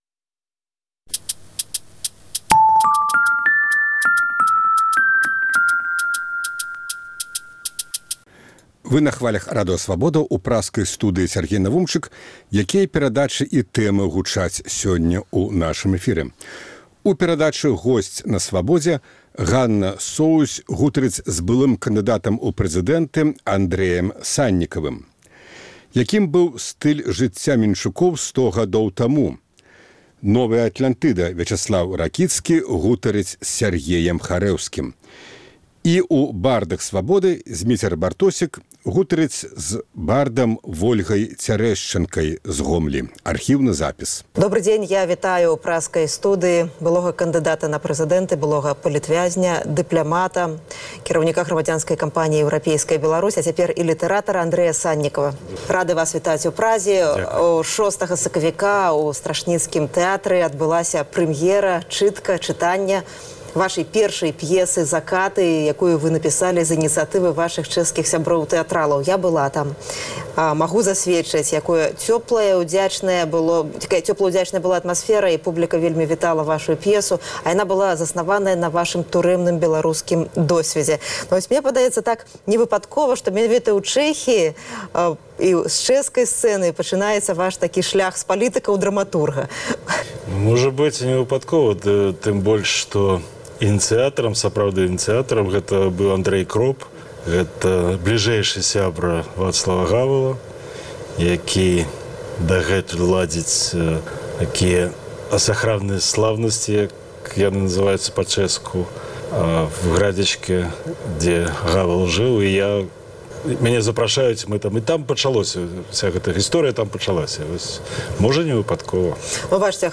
Госьць Свабоды -- былы кандыдат на прэзыдэнта, былы палітвязень, дыплямат і літаратар Андрэй Саньнікаў.